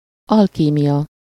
Ääntäminen
Synonyymit chemie Ääntäminen Tuntematon aksentti: IPA: /ˈsxɛi̯.kʏn.də/ Haettu sana löytyi näillä lähdekielillä: hollanti Käännös Ääninäyte 1. kémia 2. alkímia 3. aranycsinálás Suku: f .